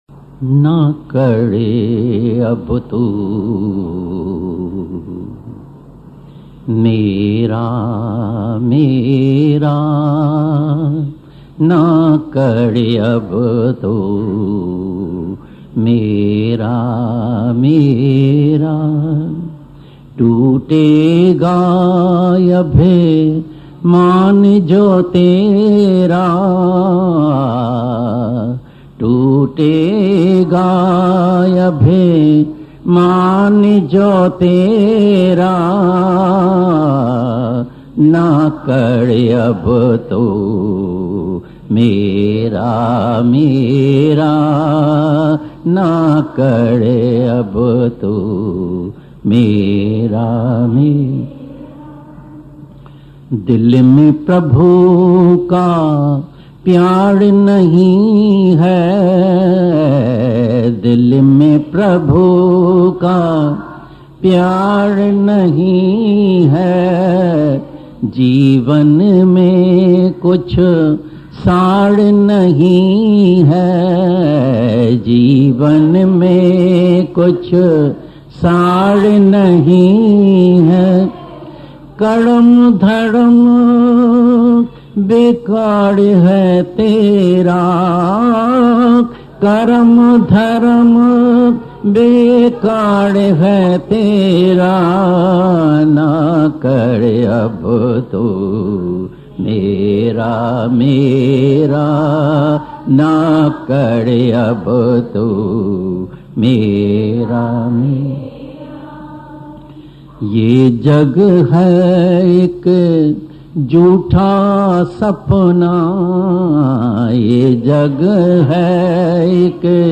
Naa-kar-ab-tu-Mera-Mera-Tutega-Abhimaan-Jo-Bhajan.mp3